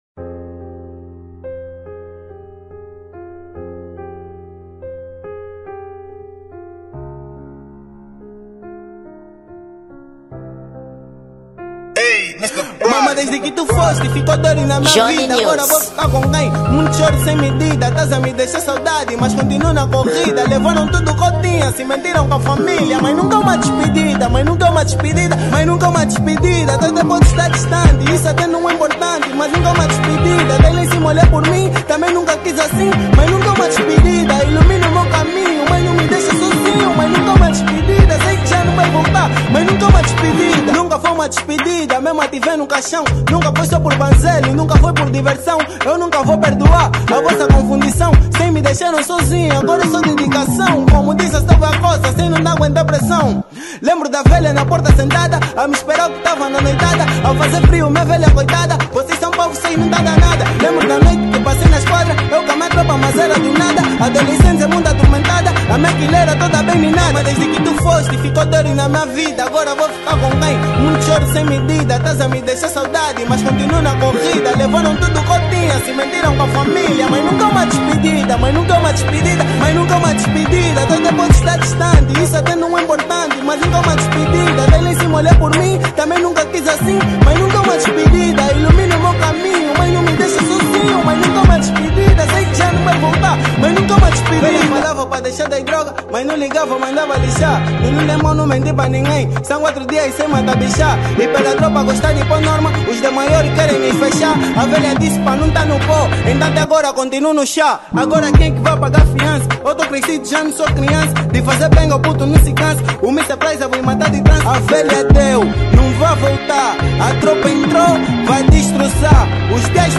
Gênero: Drill